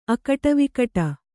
♪ akaṭavikaṭa